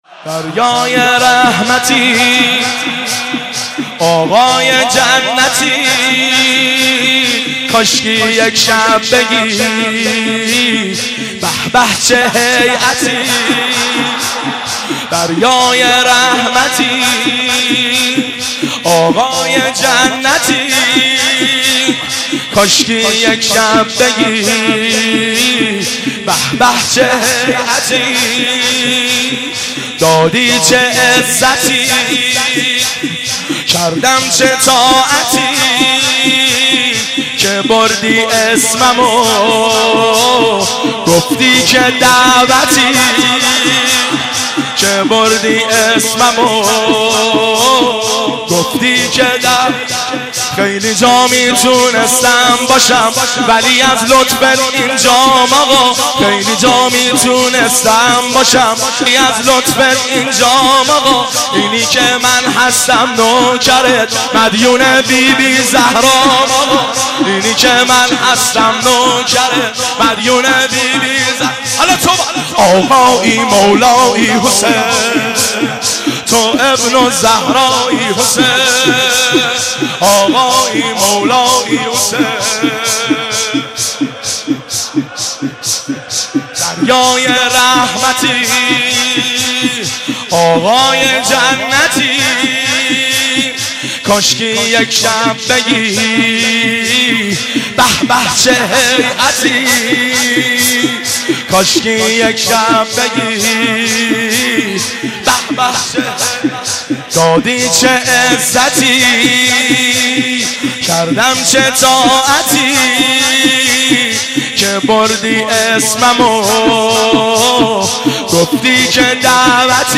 مداحی ایام فاطمیه